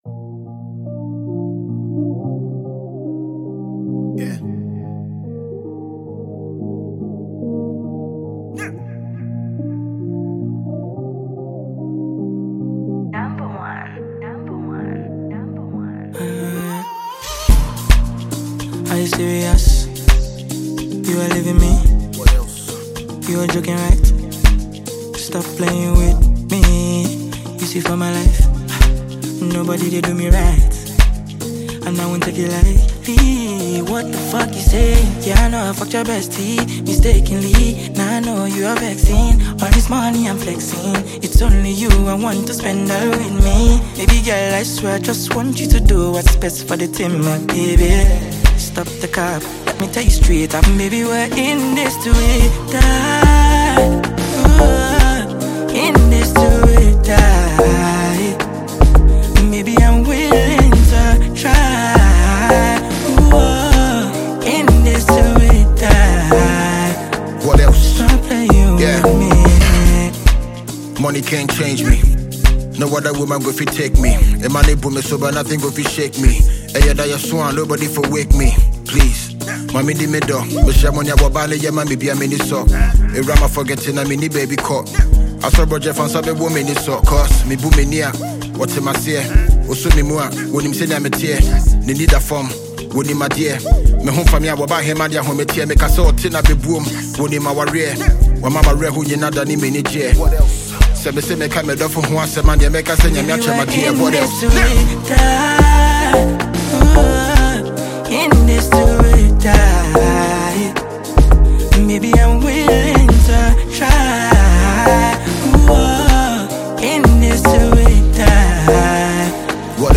Heavyweight Ghanaian rapper
Nigerian afrobeats singer